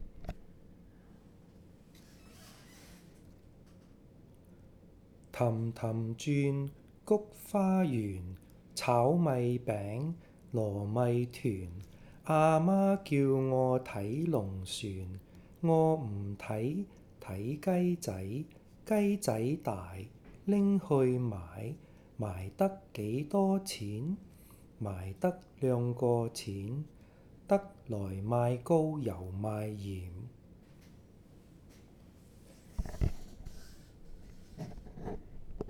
Nursery Rhyme #1: Tam4 tam4 zyun3
nursery rhyme 01.wav